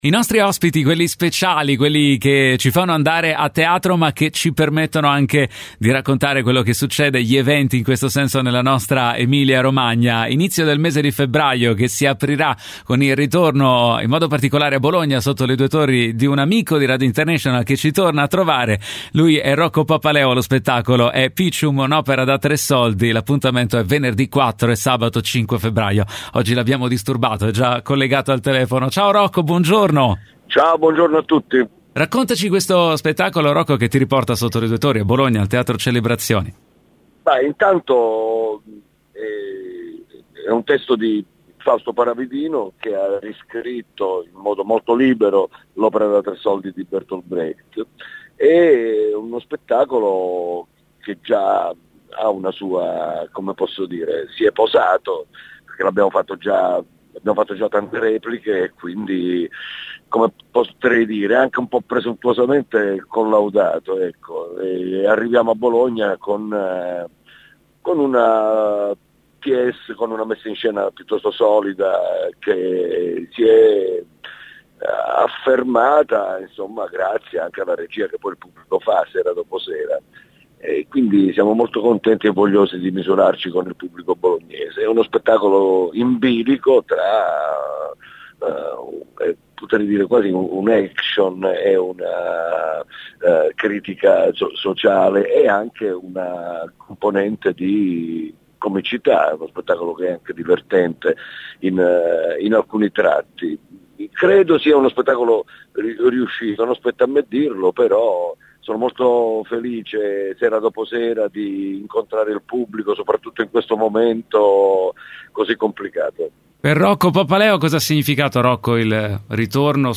roccopapaleo.mp3